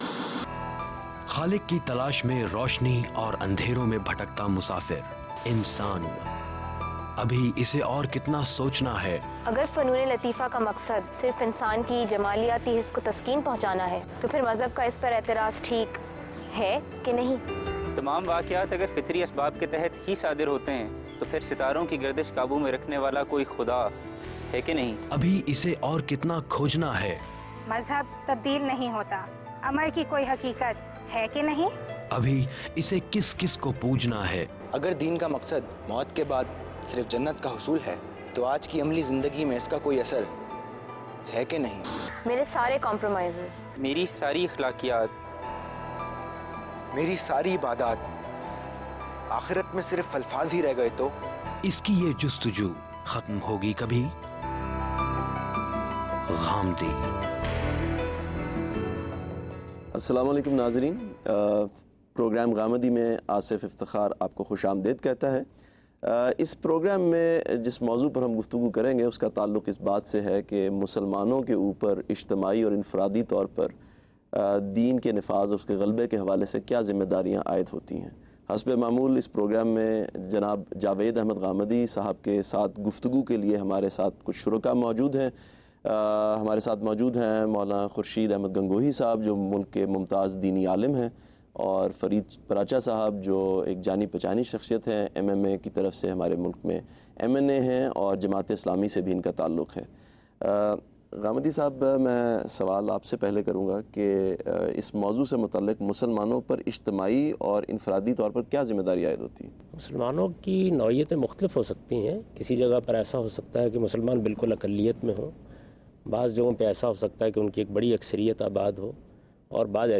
Questions and Answers on the topic “Establishing Religion” by today’s youth and satisfying answers by Javed Ahmad Ghamidi.